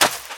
STEPS Sand, Run 14.wav